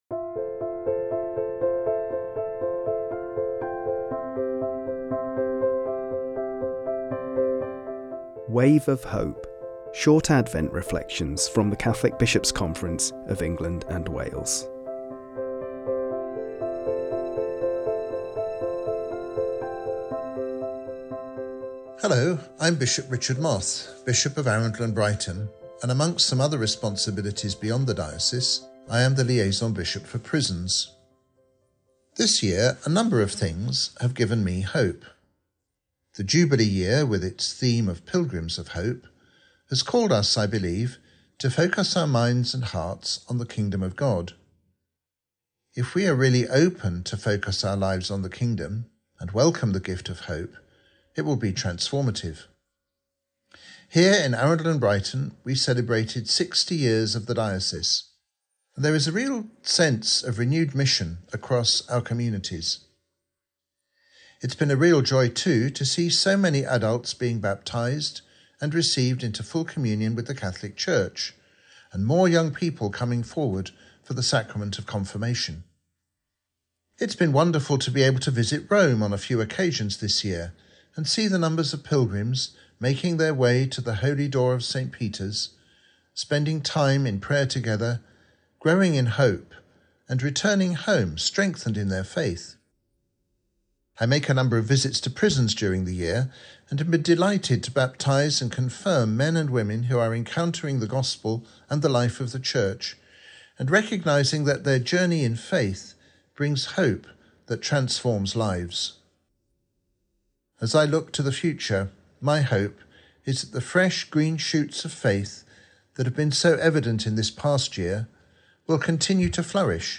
This Wave of Hope reflection for Advent comes from Bishop Richard Moth, Bishop of Arundel and Brighton and Lead Bishop for Prisons for the Catholic Bishops’ Conference of England and Wales. Bishop Moth shares how he found hope through his encounters with the men and women he met during the various prison visits he made this year: